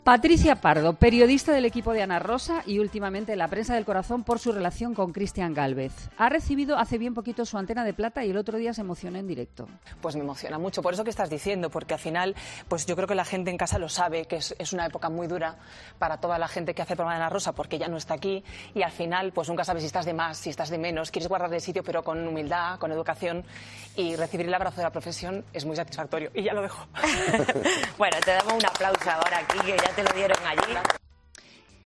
Puedes escuchar el emocionante recuerdo de Patricia Pardo en pleno directo en el programa de Ana Rosa en el siguiente audio:
Escucha a Patricia Pardo recordando a Ana Rosa entre lágrimas
Entre algunas de las frases que destacan, hemos podido escuchar cómo, entre lágrimas, Patricia Pardo hablaba de Ana Rosa en los siguientes términos: "Es una época muy dura, porque ella no está aquí, nunca sabes si estás de más o de menos", ha dicho emocionada, entre otras cosas, que puedes escuchar en el audio anterior.